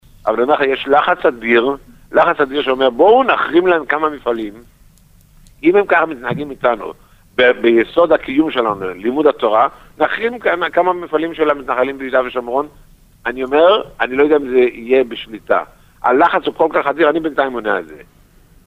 אמר חבר-הכנסת ר' מאיר פרוש